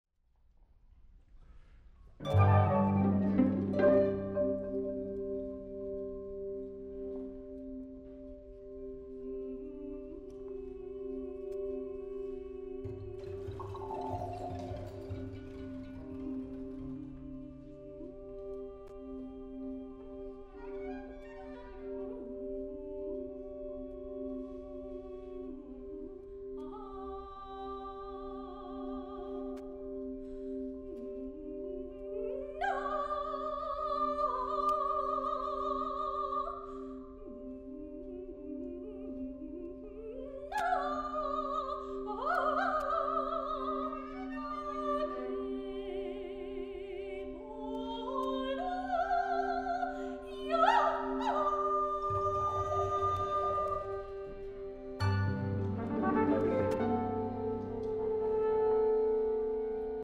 for Soprano and Fifteen Players